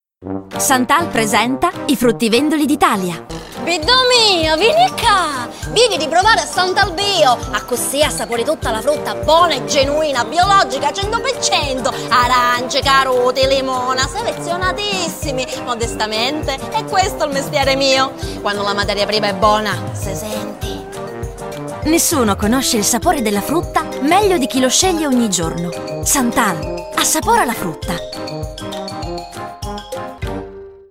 Italiano e Siciliano, spiritoso, energico